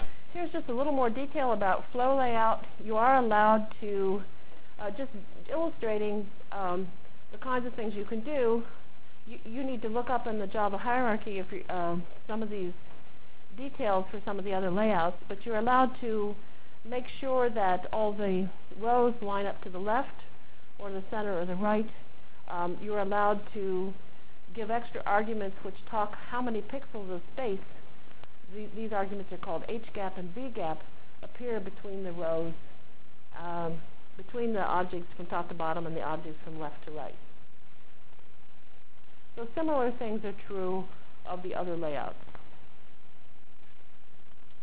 From Feb 3 Delivered Lecture for Course CPS616 -- Java Lecture 4 -- AWT Through I/O CPS616 spring 1997 -- Feb 3 1997.